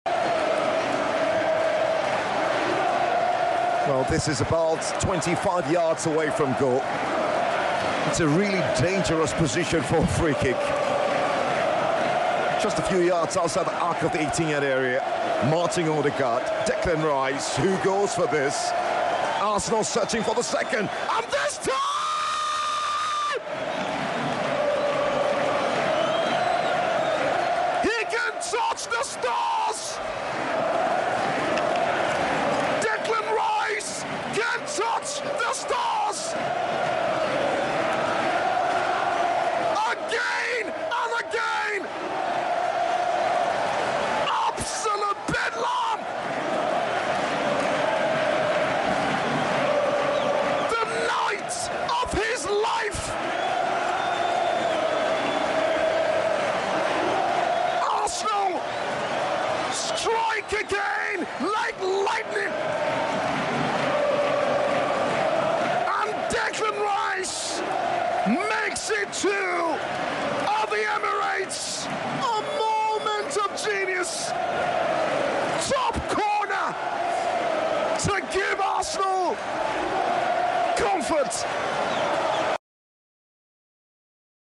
My Description of Declan Rice Second Freekick while on radio commentary!